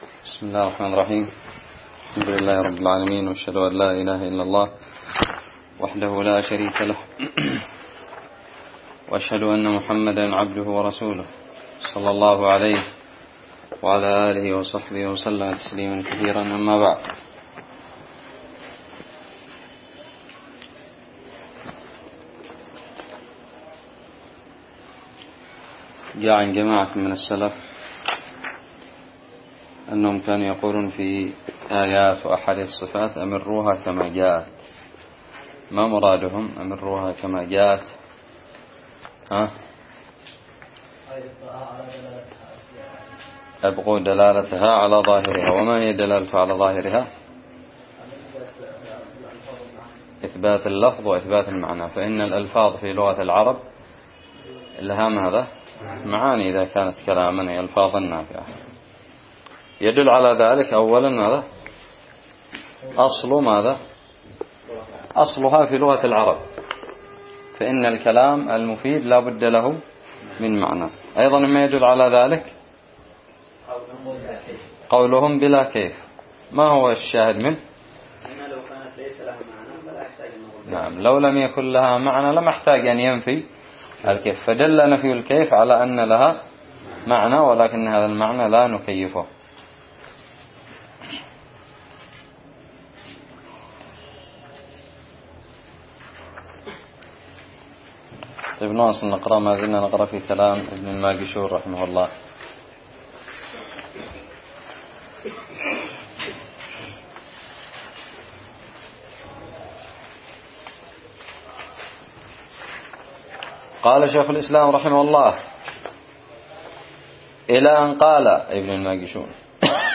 الدرس التاسع من شرح متن الحموية
ألقيت بدار الحديث السلفية للعلوم الشرعية بالضالع